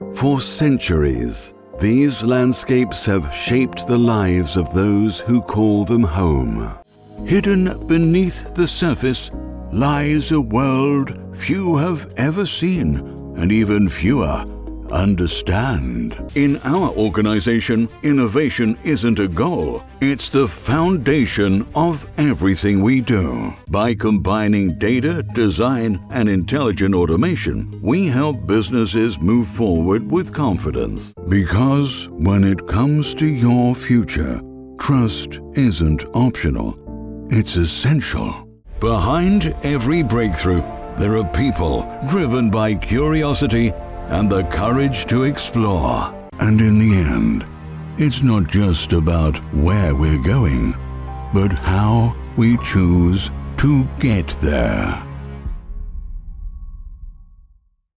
Male
English (British)
Adult (30-50), Older Sound (50+)
I have a broadcast quality studio with a Neumann U87 microphone and Source Connect Standard capability.
Corporate
Business Scripts